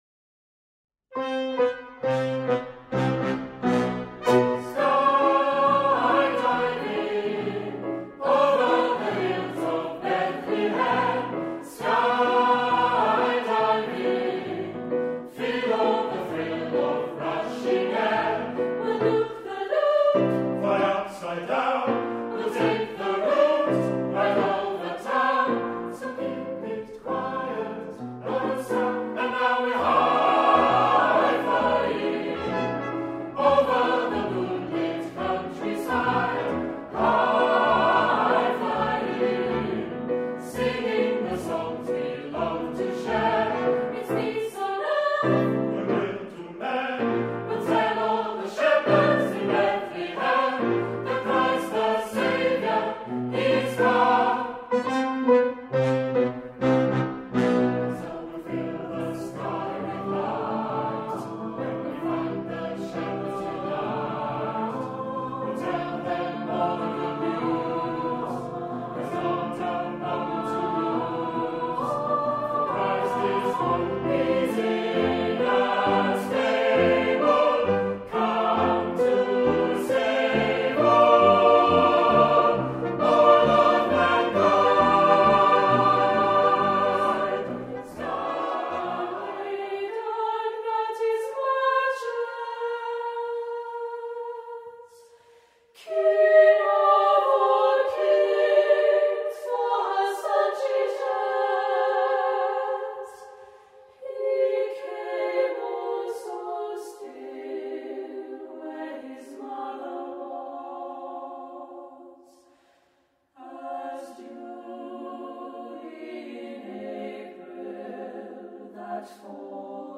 there is much emphasis on strong melodic content